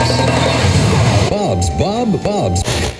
80BPM RAD7-L.wav